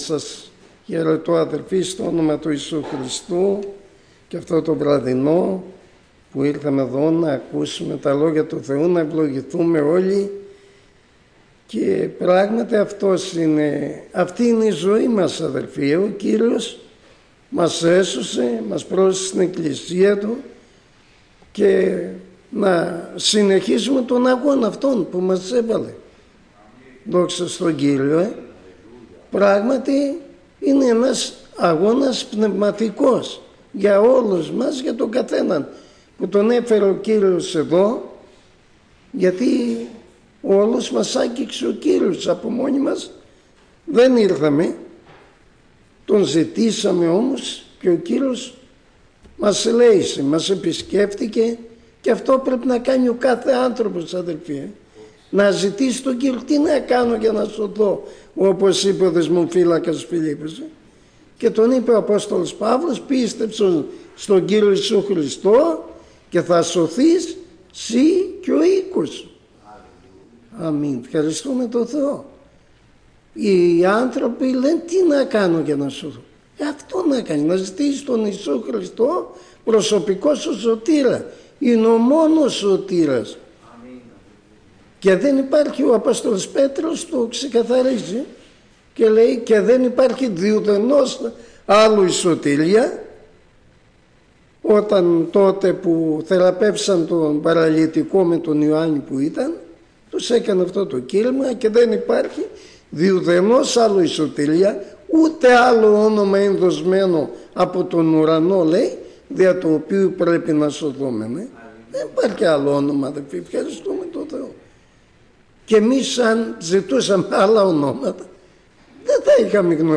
Κήρυγμα Παρασκευής